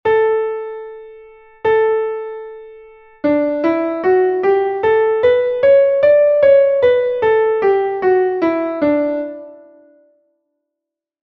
escala_re_maior_con_la.mp3